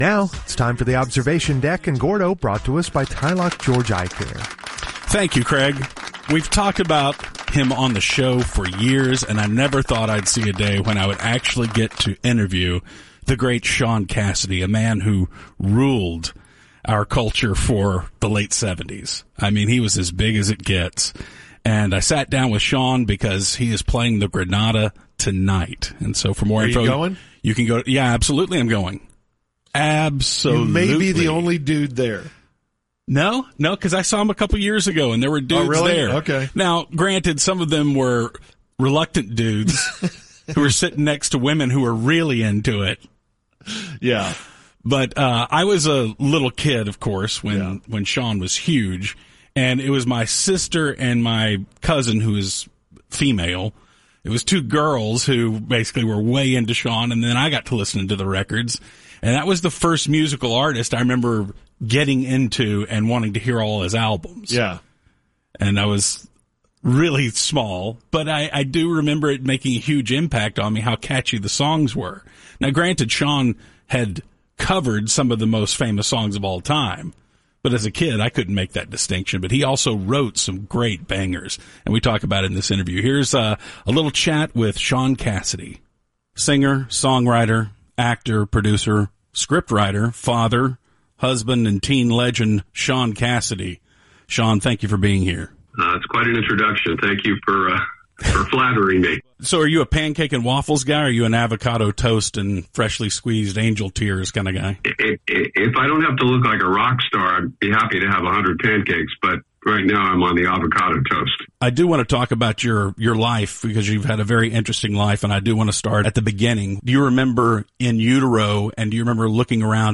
interviews Shaun Cassidy